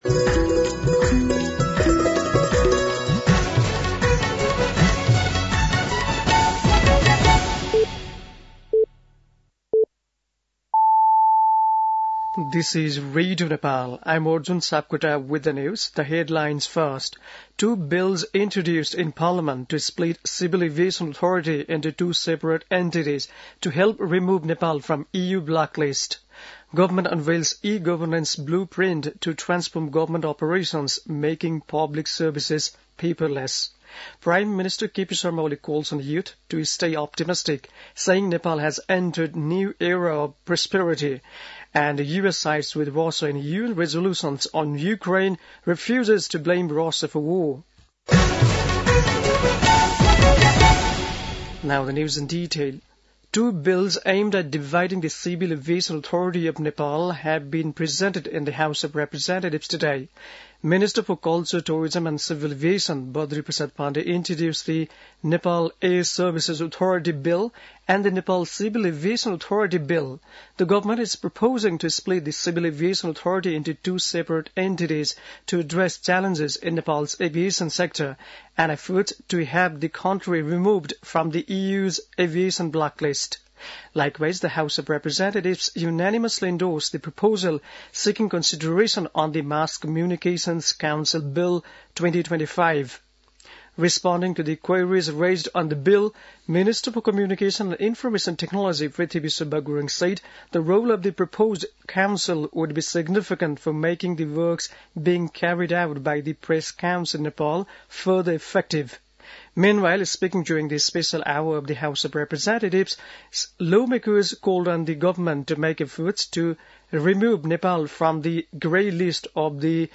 बेलुकी ८ बजेको अङ्ग्रेजी समाचार : १४ फागुन , २०८१